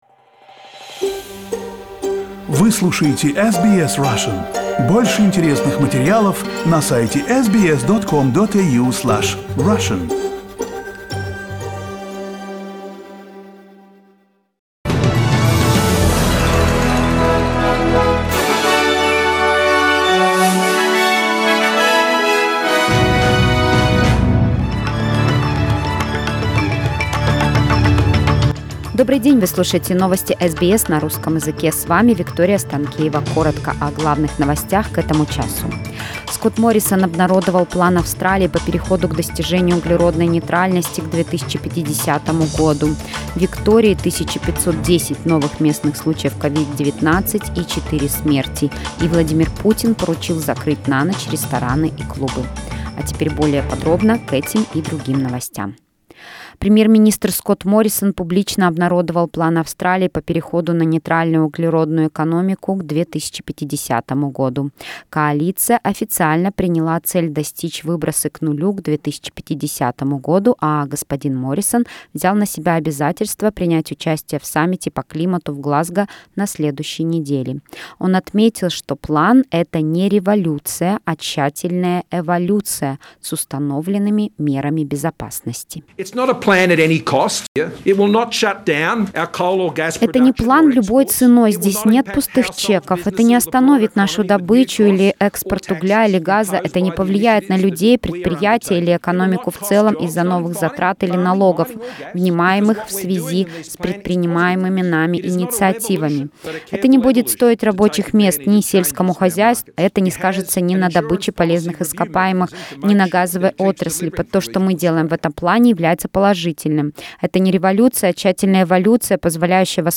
SBS news in Russian - 26.10